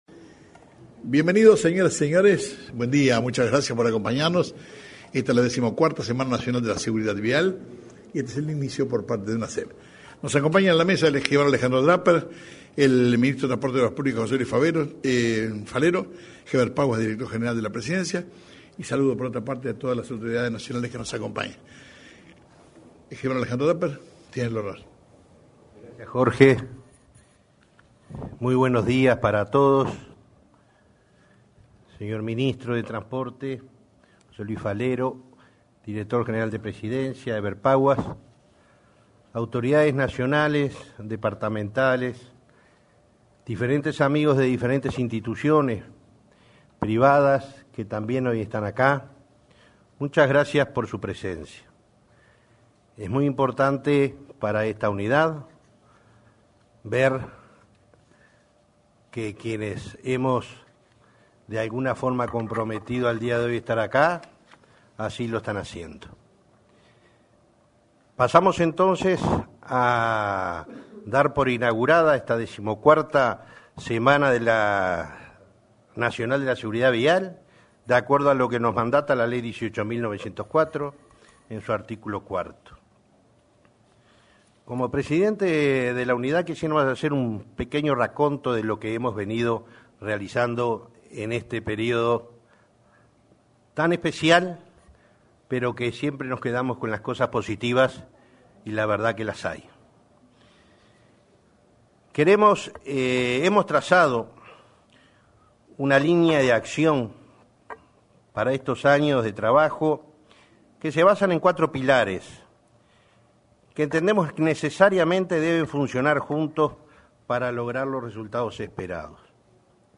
Apertura de la XIV Semana Nacional de la Seguridad Vial